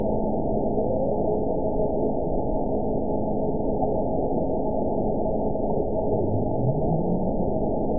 event 921746 date 12/18/24 time 08:18:26 GMT (11 months, 2 weeks ago) score 9.11 location TSS-AB02 detected by nrw target species NRW annotations +NRW Spectrogram: Frequency (kHz) vs. Time (s) audio not available .wav